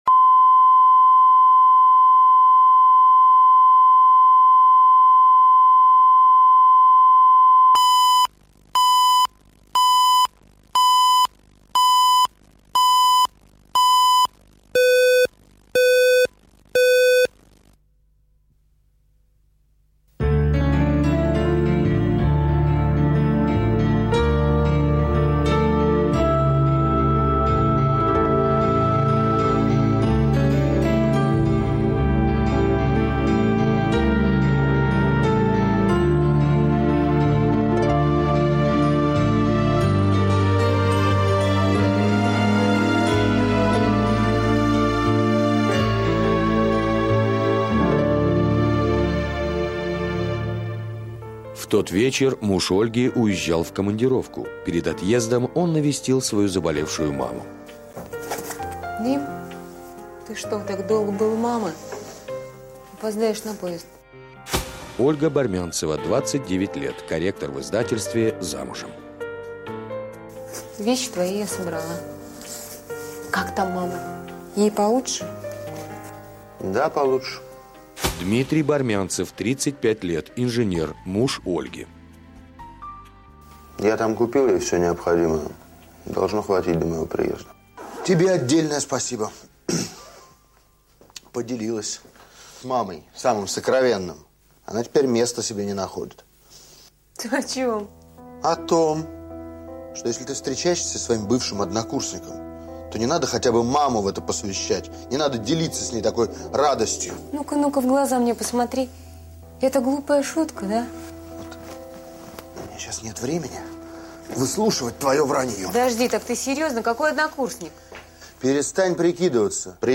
Аудиокнига Где ты был?
Прослушать и бесплатно скачать фрагмент аудиокниги